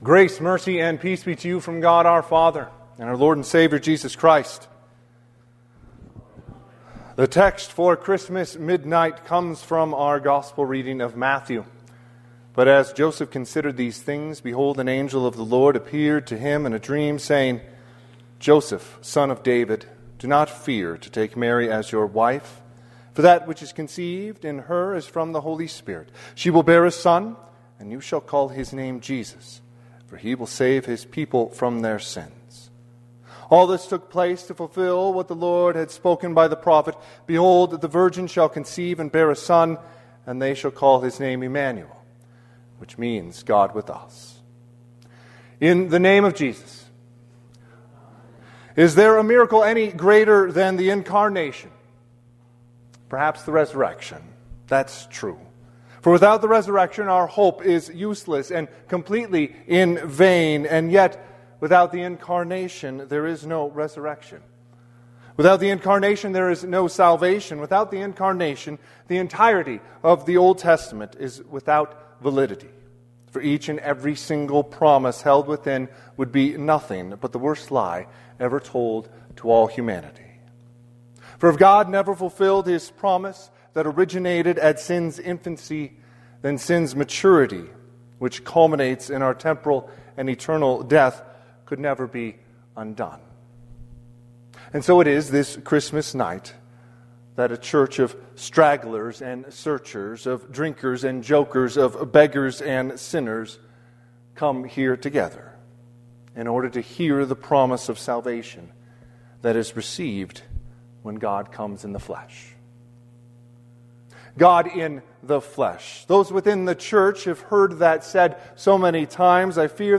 Christmas Eve Service - 11PM